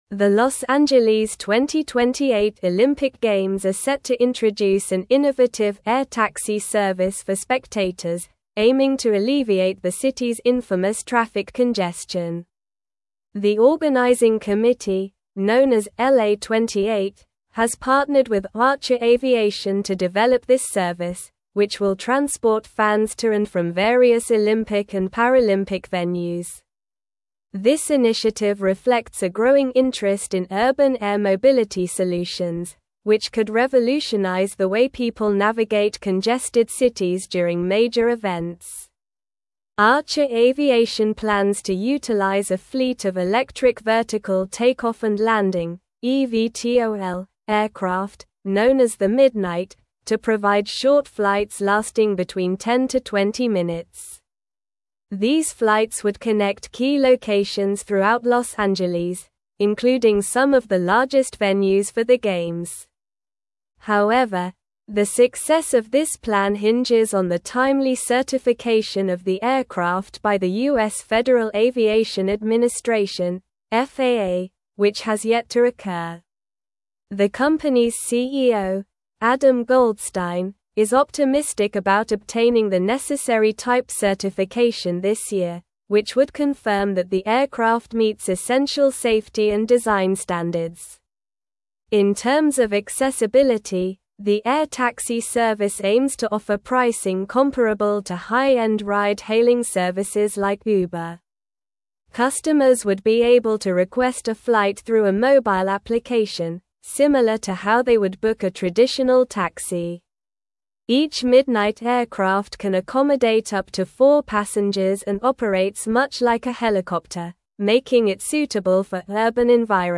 Slow
English-Newsroom-Advanced-SLOW-Reading-LA-2028-Olympics-Introduces-Innovative-Air-Taxi-Service.mp3